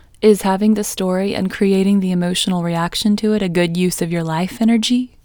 OUT Technique Female English 24